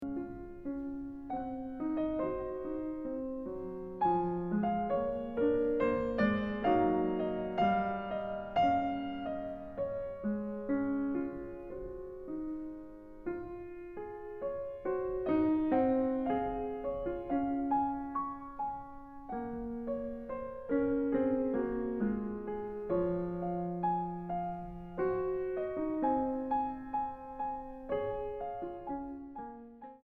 Música mexicana para piano de los siglos XX y XXI.
piano